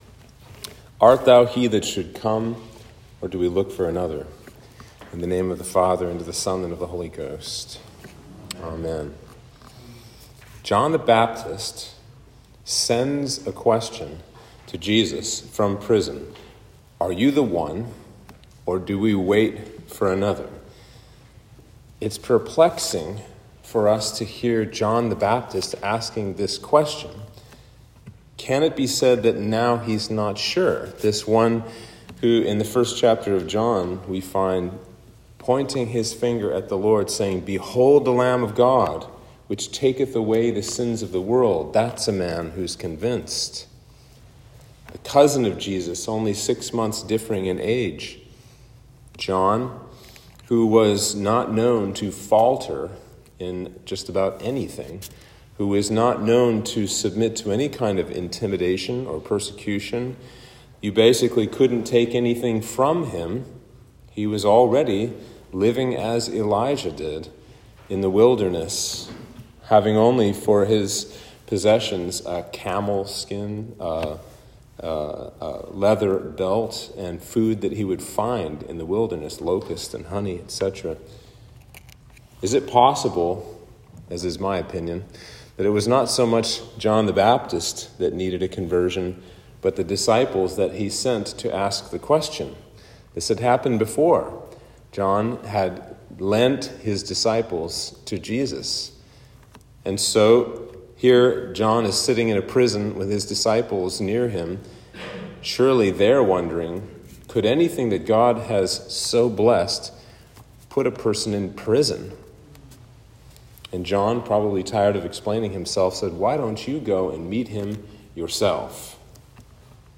Sermon for Advent 3